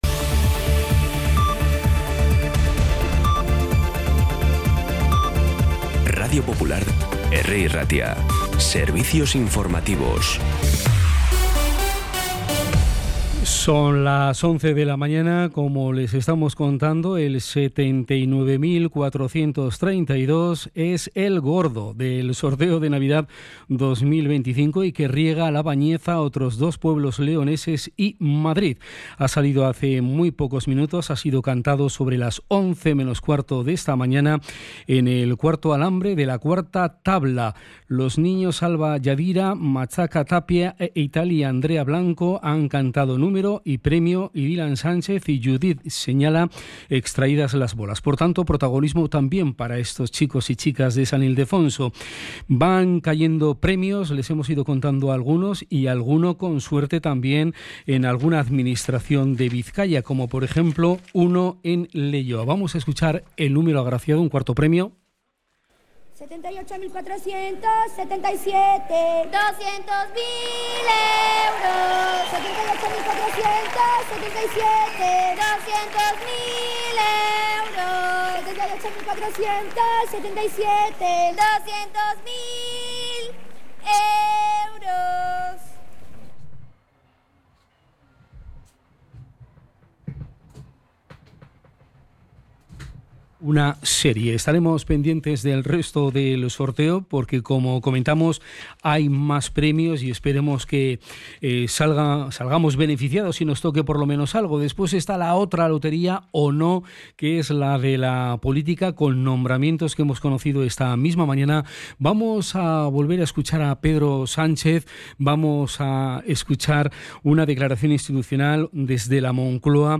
Las noticias de Bilbao y Bizkaia del 22 de diciembre a las 11
Los titulares actualizados con las voces del día. Bilbao, Bizkaia, comarcas, política, sociedad, cultura, sucesos, información de servicio público.